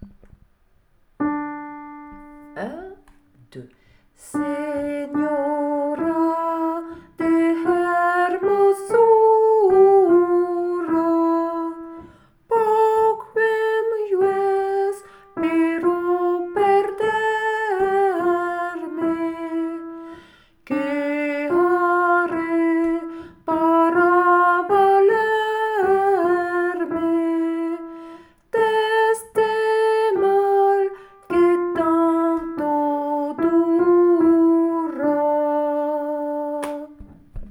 Version à écouter pour la prononciation et la mise en place des syllabes
Soprano
senora-de-hermosura-soprano.wav